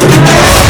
speeder_boost2.wav